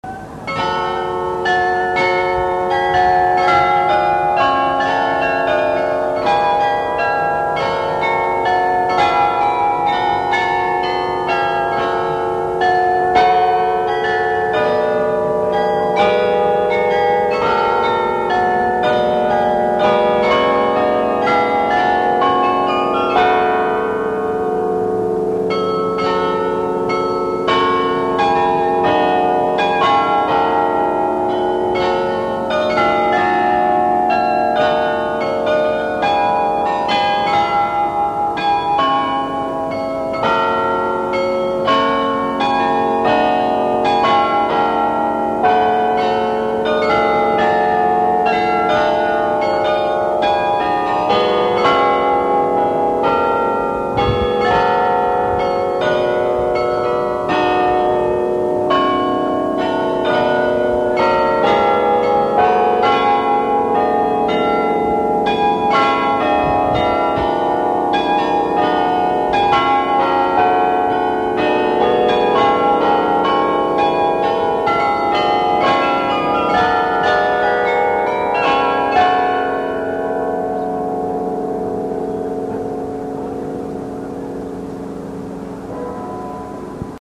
The Carillon
Traditional May-song of Gouda, c. 17th century. Automatic chime, Saint Jans tower, Gouda.